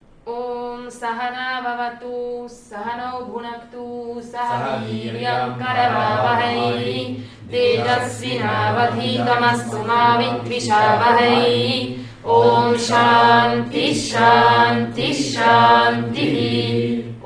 Vedische Rezitationen für den Weltfrieden